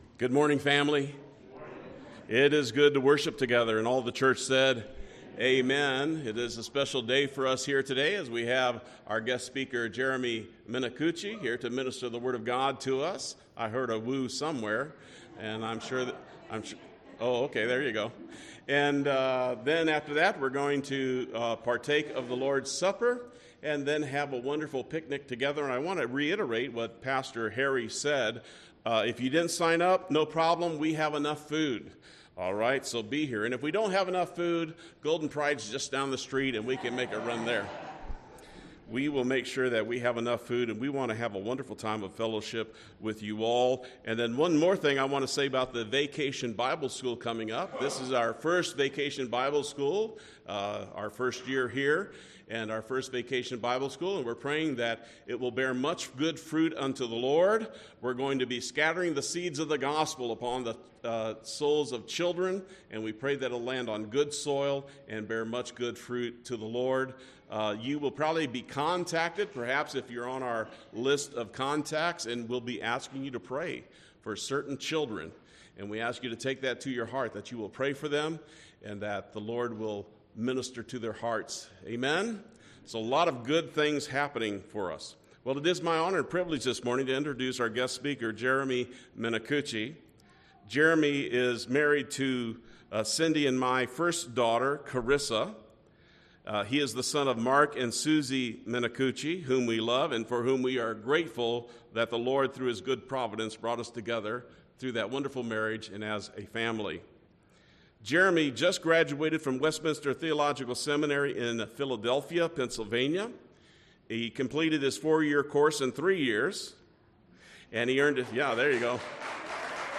This sermon explores the biblical teaching found in Psalm 16, providing practical application for daily Christian living.